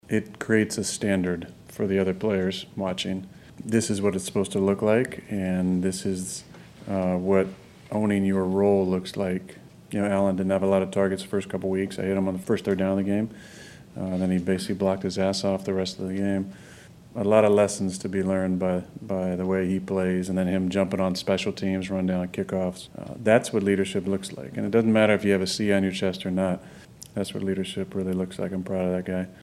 The wise old sage of the locker room met the media on Thursday to explain why guys like Lazard are the perfect example of how all 53 players are needed to win.